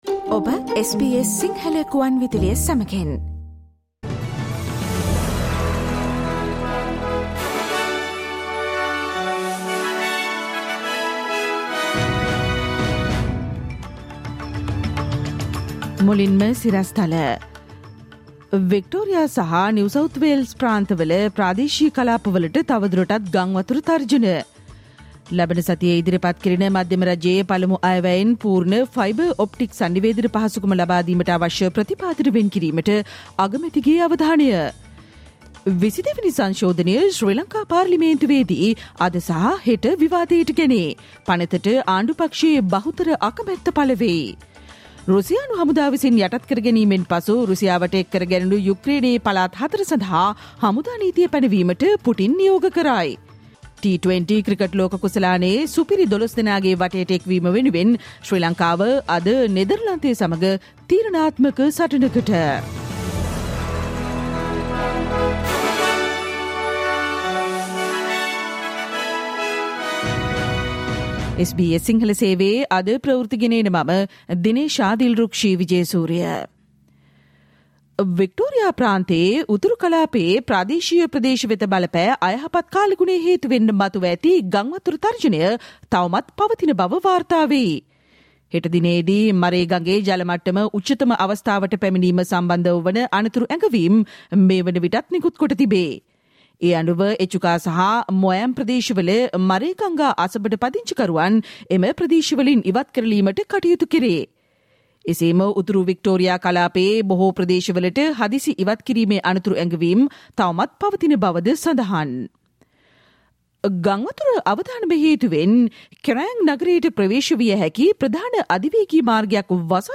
Listen to the SBS Sinhala Radio news bulletin on Thursday 20 October 2022